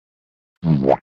Sad
Boo-womp.mp3